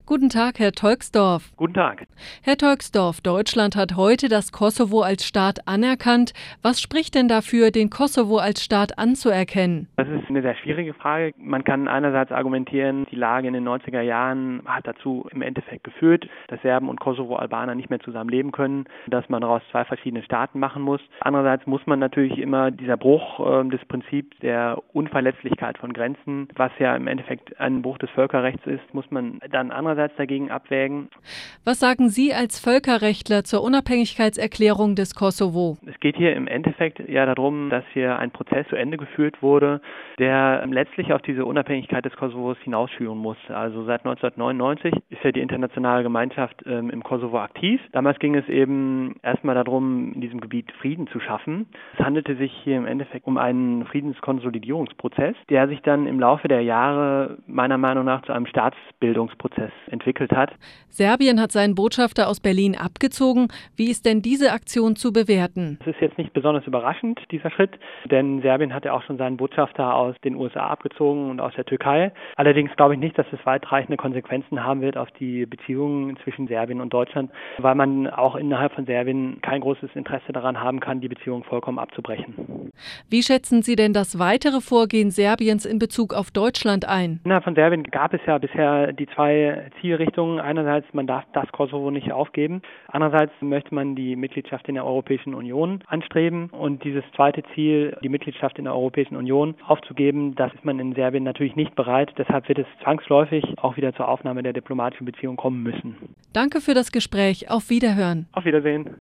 Audio-Interview